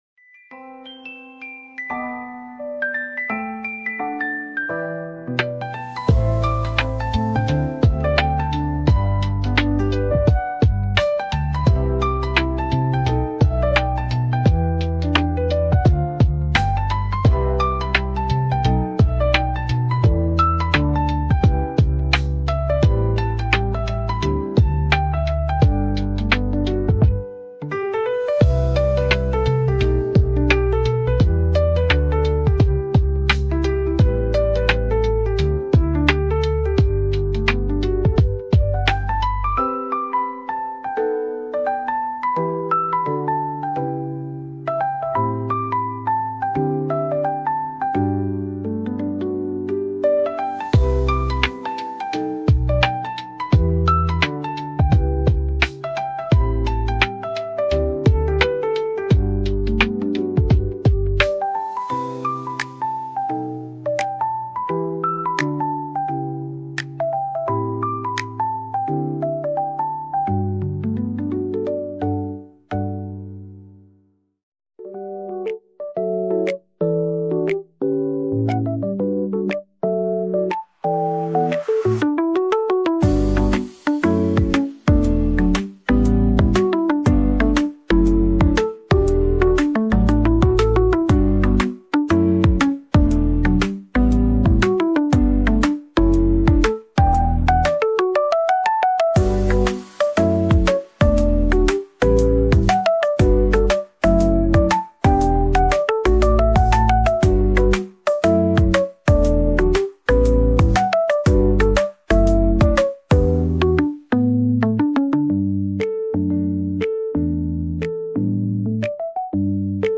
lo-fi music